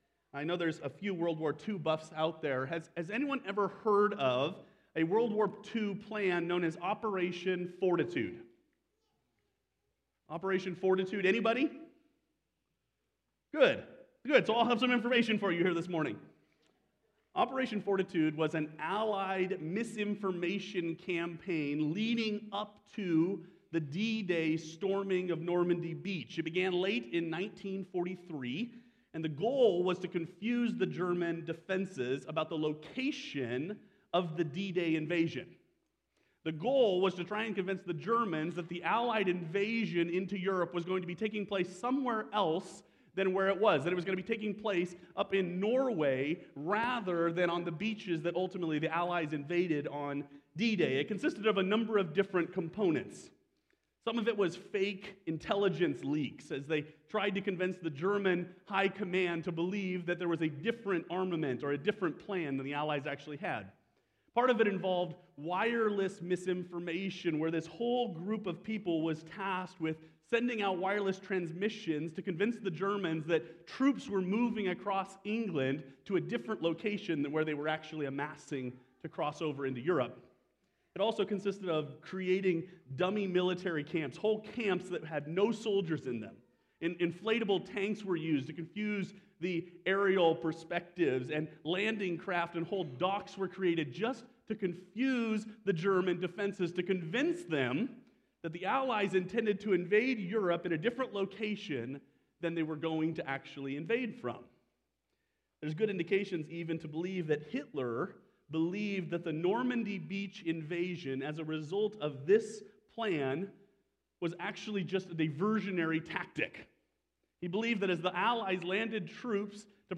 Sermon Archives